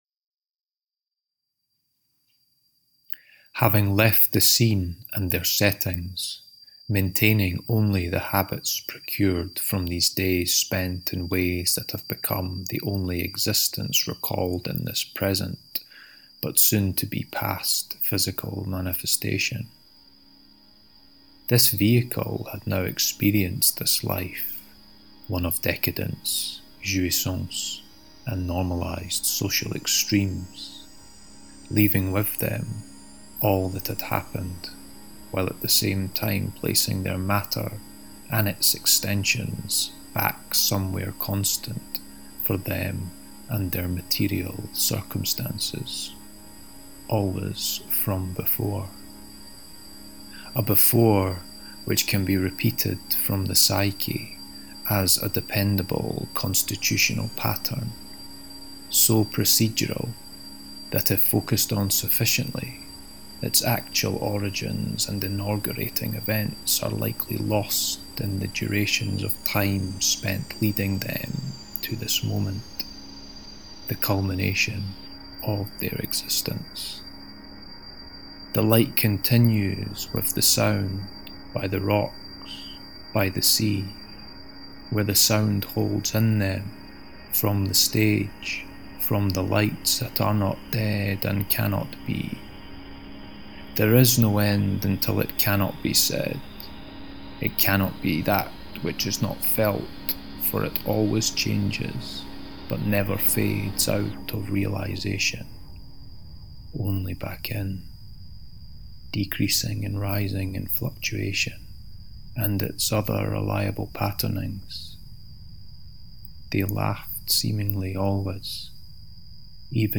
This short recorded reading, with accompanying sounds, is from a section entitled 'Tapering Times'.